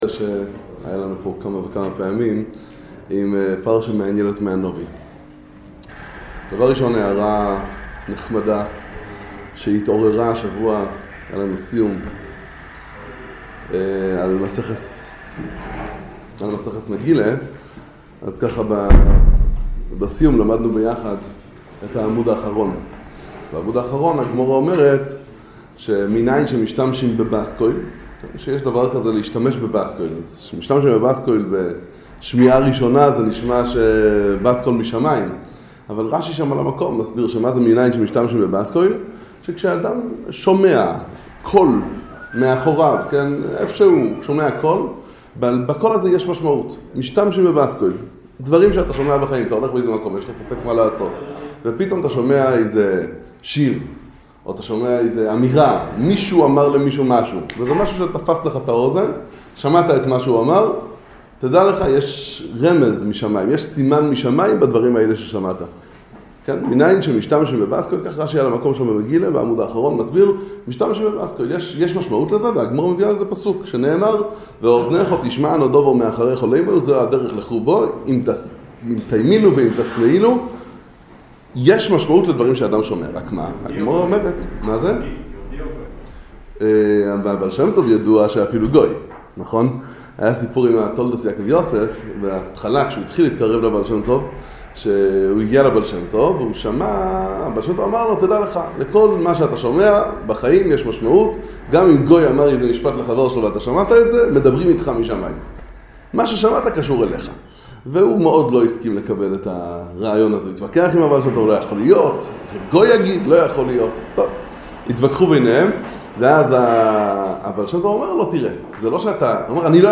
שיחה בכולל לערנען לצעירים חרדים עצמאים